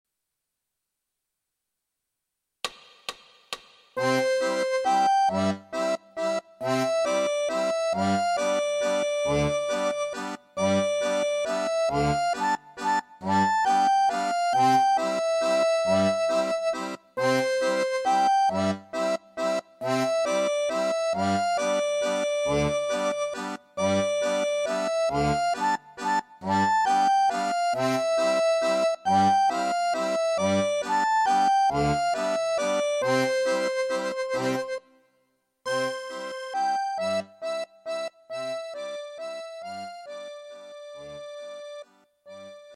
Fisarmonica, Capitolo V, allargamenti stringimenti
13 – L'Alpino Ignoto (Valzer - Unite)